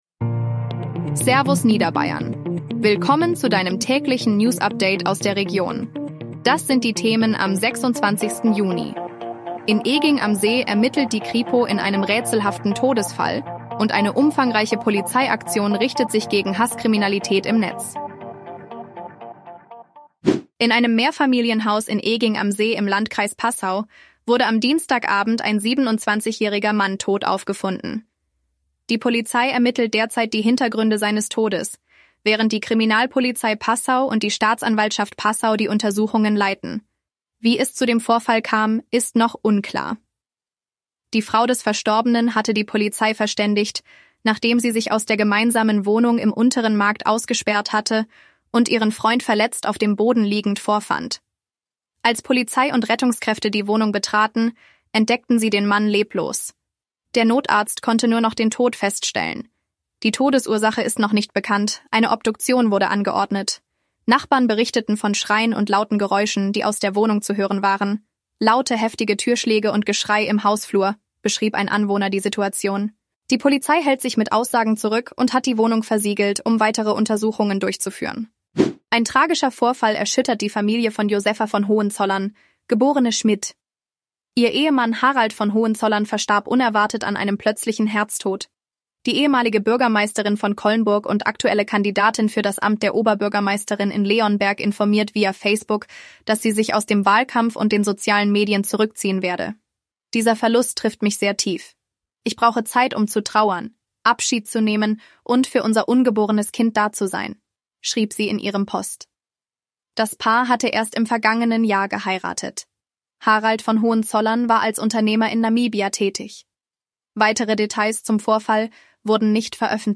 Dein tägliches News-Update